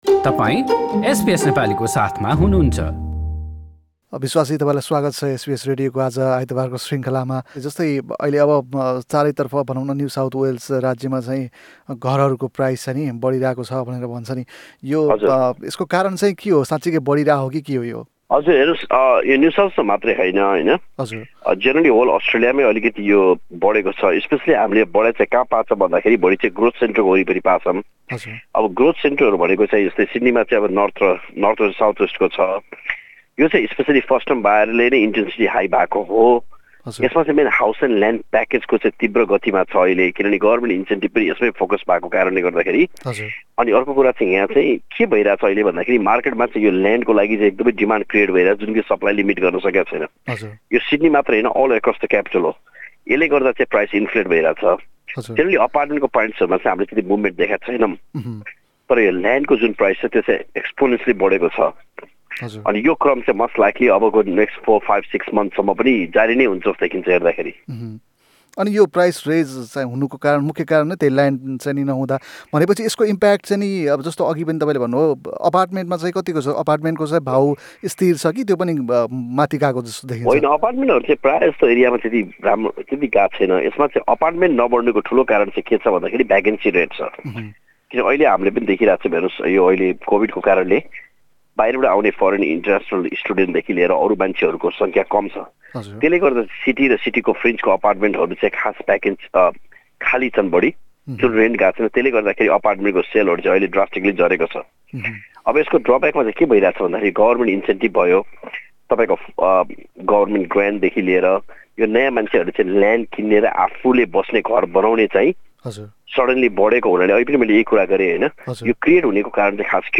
प्रश्नोत्तर